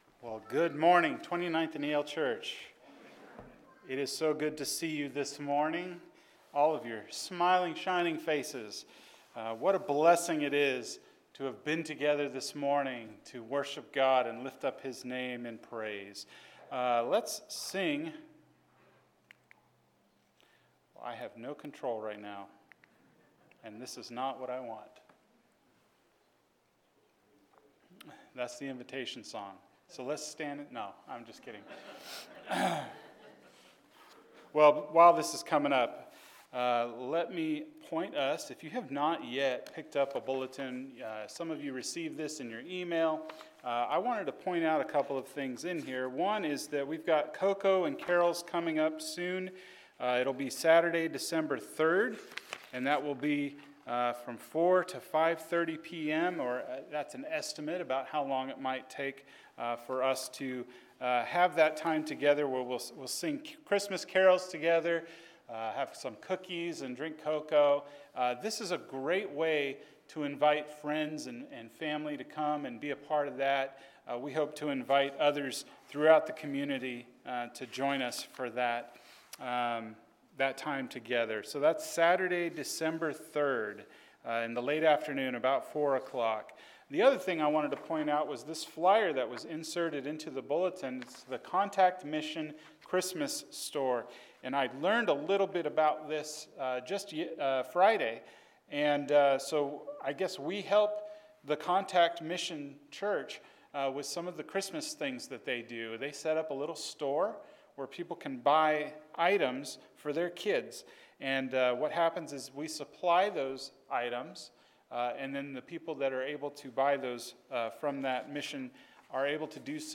Seven — Forgiveness – Sermon — Midtown Church of Christ